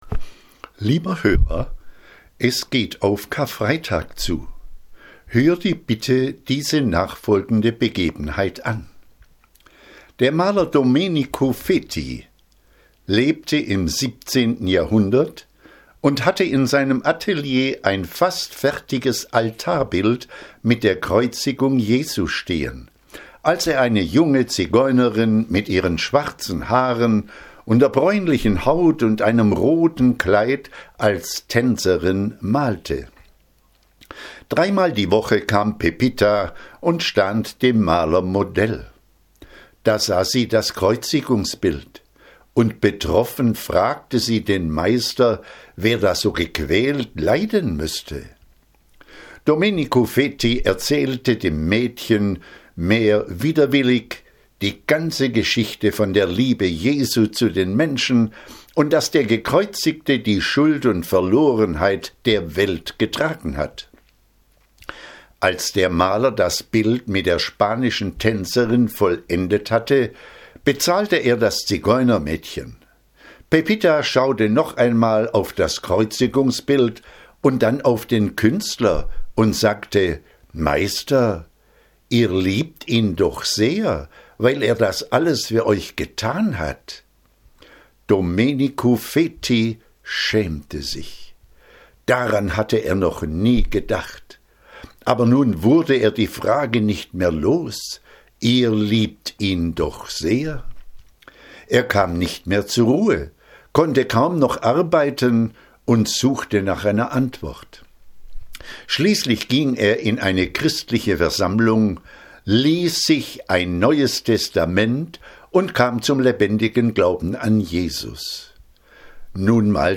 Letzte Predigten